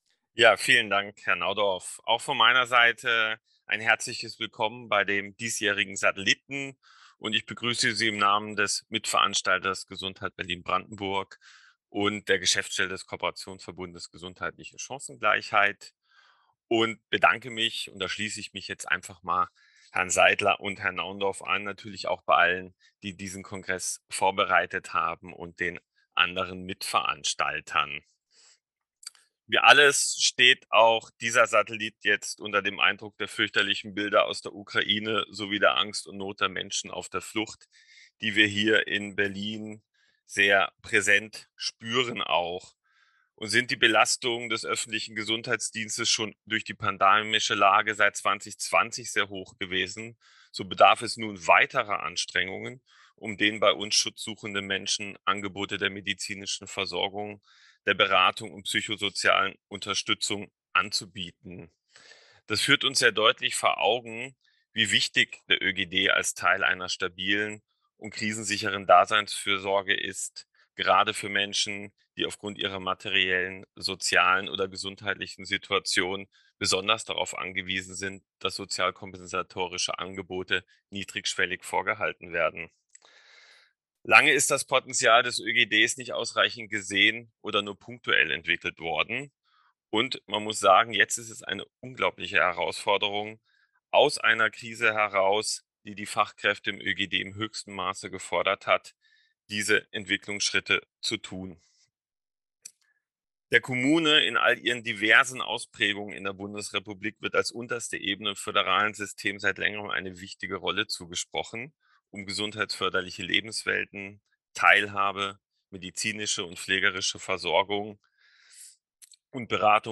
Satellitenveranstaltung zum Kongress Armut und Gesundheit
Grußwort